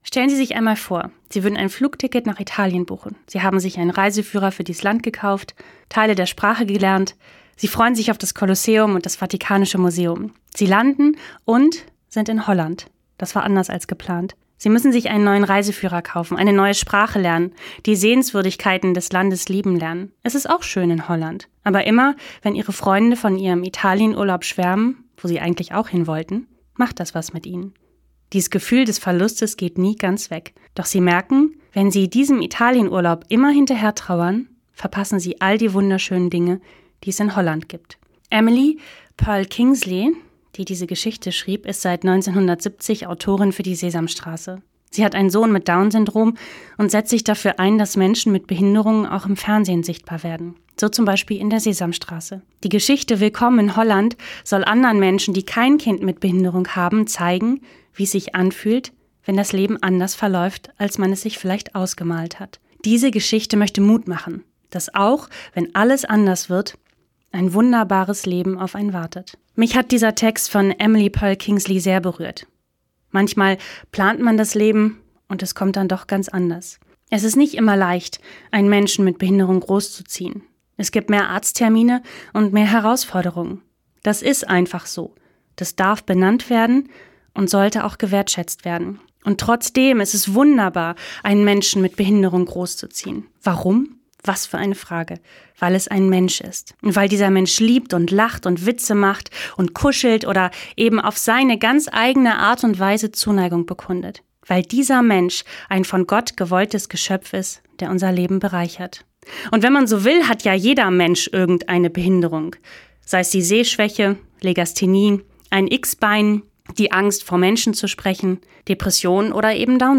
Radioandacht vom 9. Mai